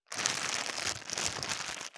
630コンビニ袋,ゴミ袋,スーパーの袋,袋,買い出しの音,ゴミ出しの音,袋を運ぶ音,
効果音